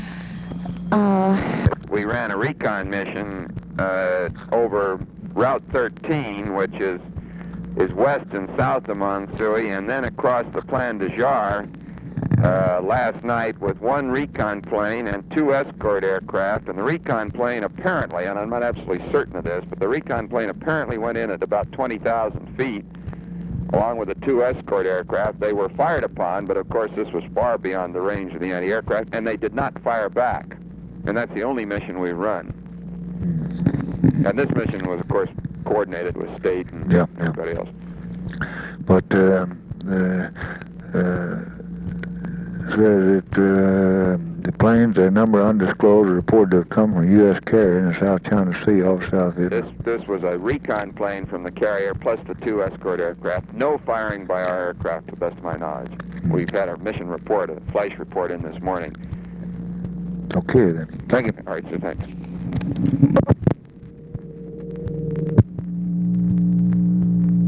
Conversation with ROBERT MCNAMARA, June 26, 1964
Secret White House Tapes